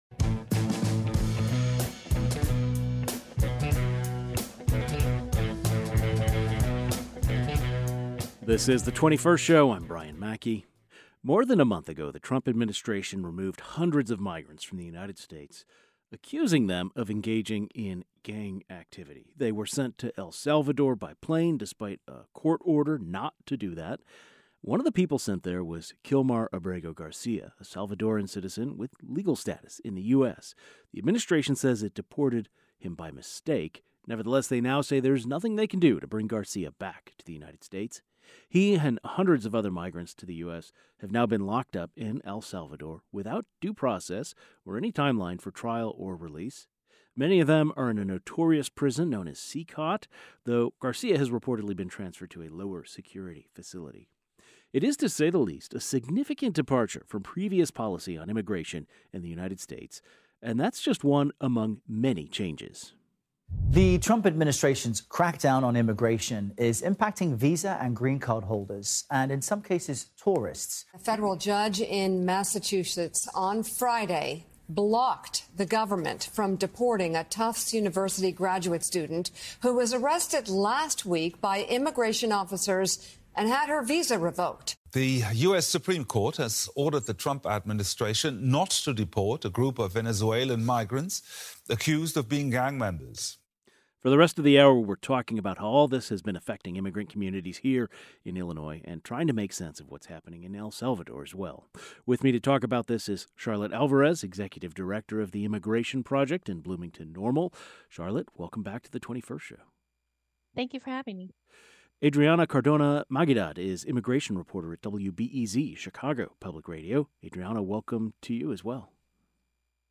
A panel of immigration experts share their views on what's going on with the U.S. immigration policies three months into the Trump administration.